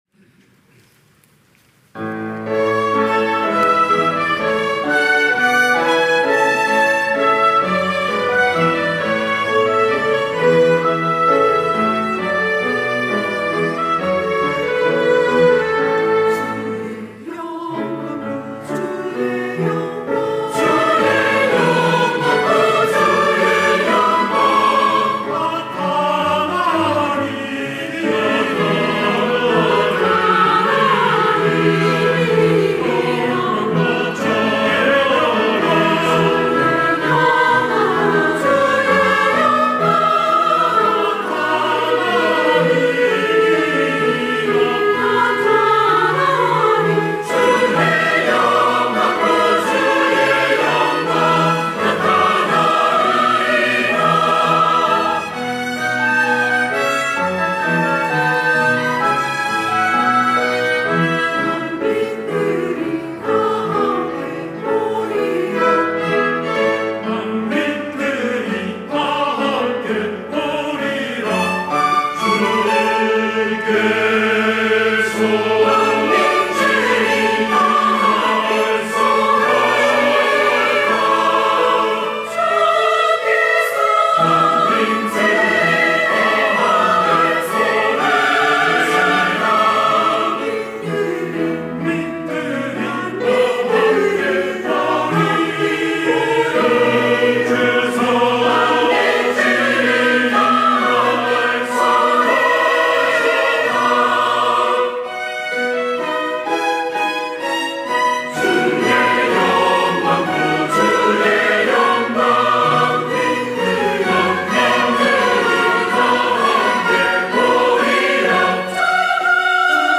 할렐루야(주일2부) - 주의 영광
찬양대